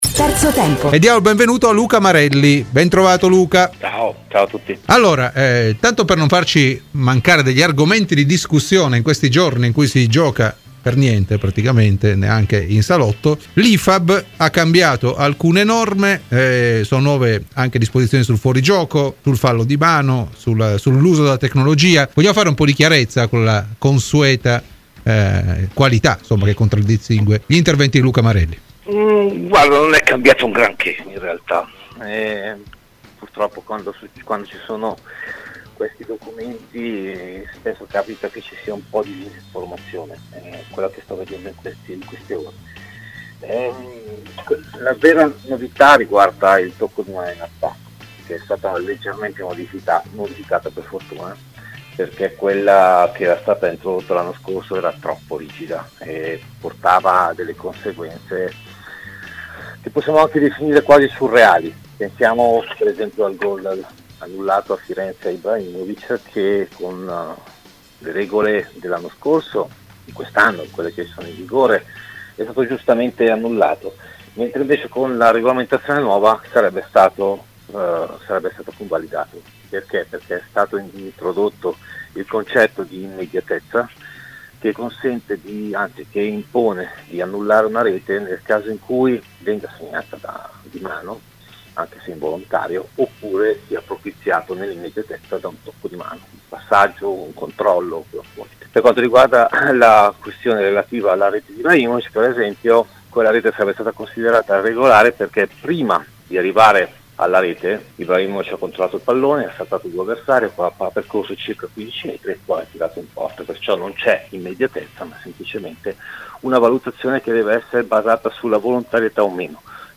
Intervenuto ai microfoni di Radio Bianconera